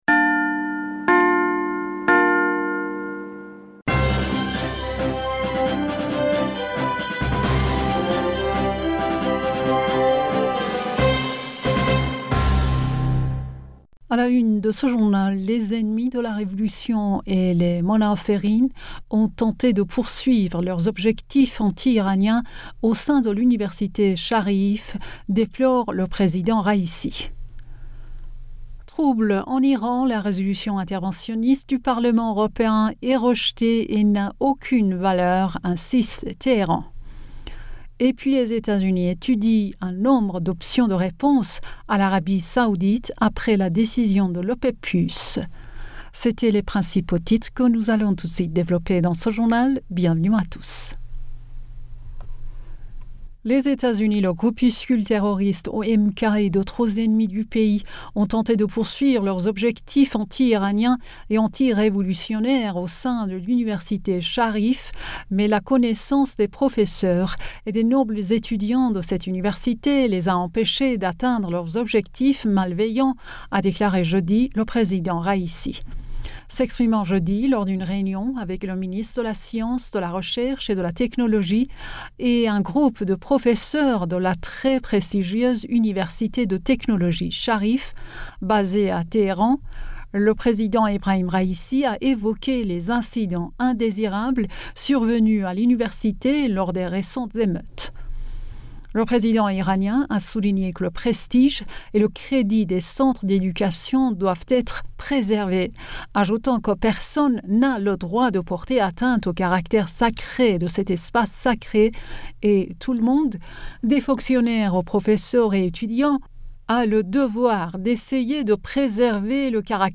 Bulletin d'information Du 07 Octobre